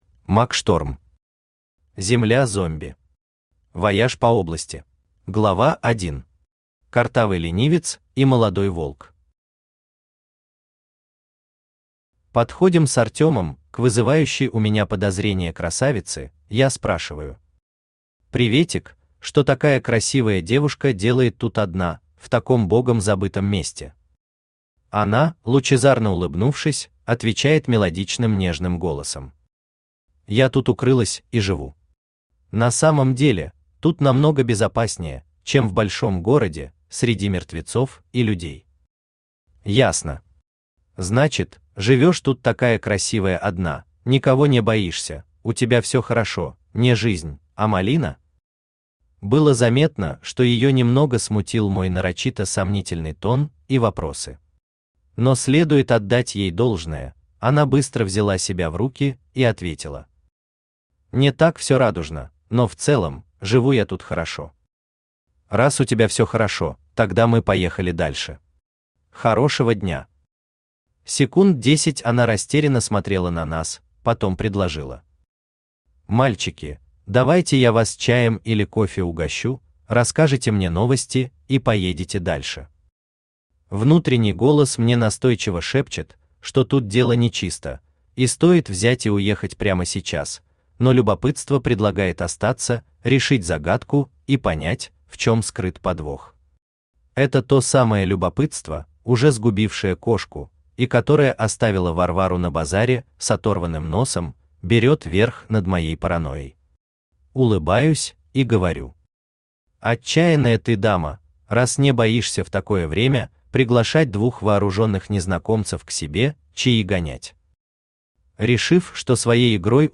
Аудиокнига Земля зомби. Вояж по области | Библиотека аудиокниг
Вояж по области Автор Мак Шторм Читает аудиокнигу Авточтец ЛитРес.